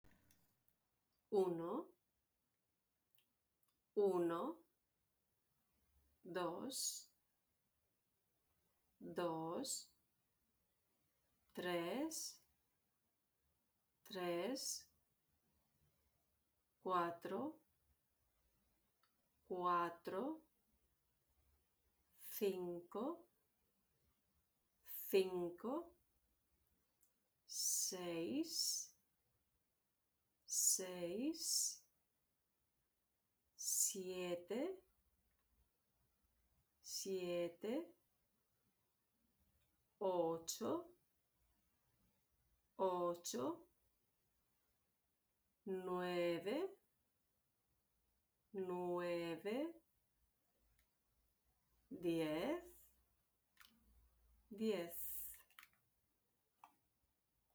Please, pay the track and repeat these out loud to get the pronunciation just right:
Please, pay the track and repeat these out loud to get the pronunciation just right: Number 1 to 10 in Spanish Uno (oo-noh) Dos (dohs) Tres (tres) Cuatro (kwah-troh) Cinco (seen-koh) Seis (says) Siete (syeh-teh) Ocho (oh-choh) Nueve (nweh-veh) Diez (dyes) Why is it important to learn the numbers?